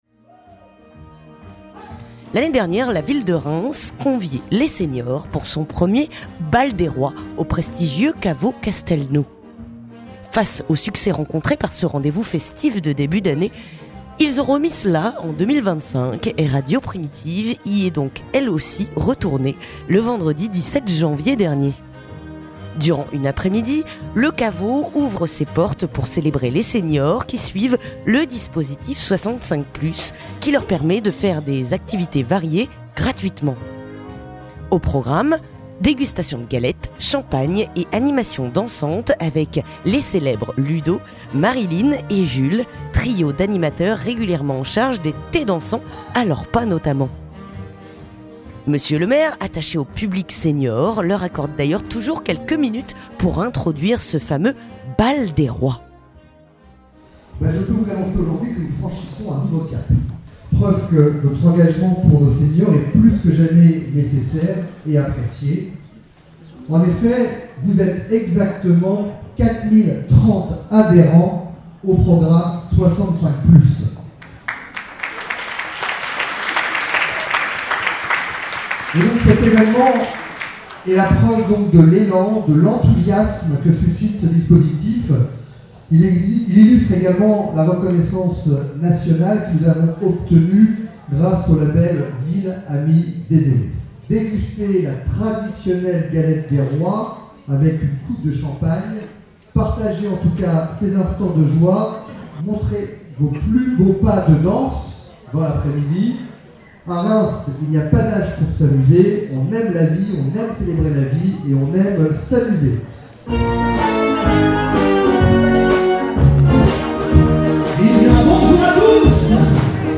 Reportage au caveau Castelnau
Pour la deuxième année consécutive, la ville de Reims conviait les séniors du dispositif 65+ à une après-midi dansante dans le prestigieux caveau Castelnau, c'était le Bal de Rois